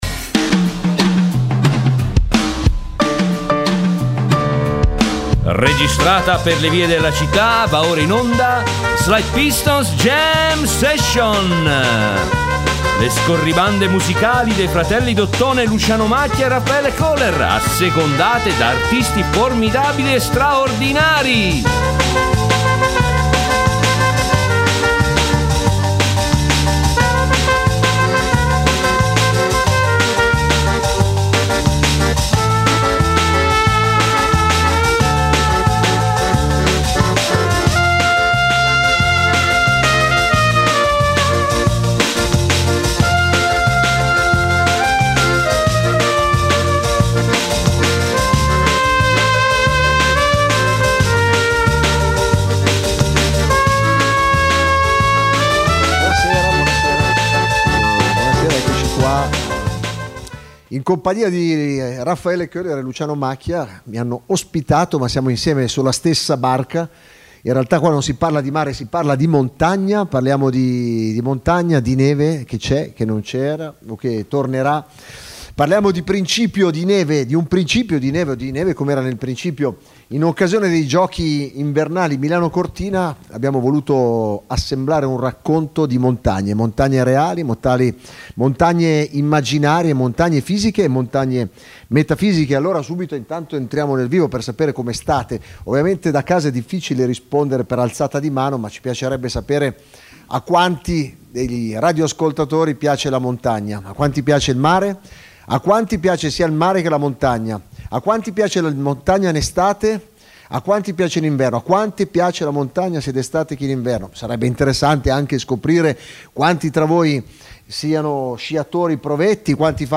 In onda le scorribande musicali dei due suonatori d’ottone in giro per la città...